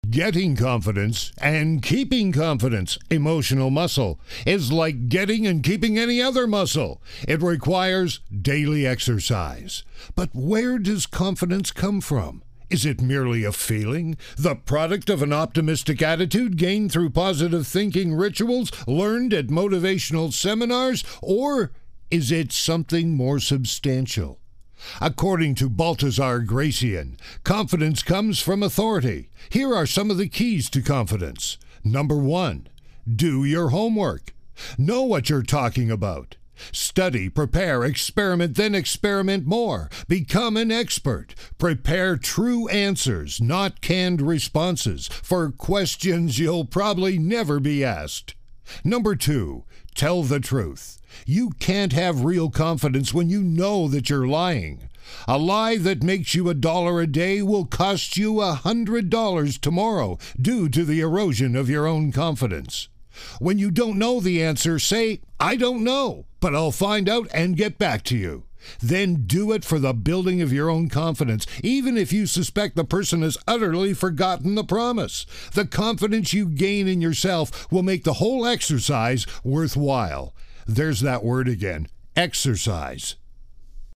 Tags: Voice Advertising Voice Actor Voice Over Media